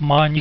money16b.wav